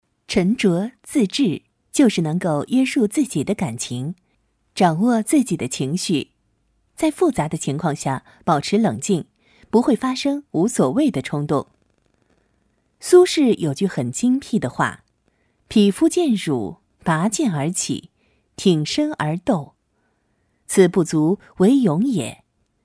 Voces en chino mandarín.
Locutoras chinas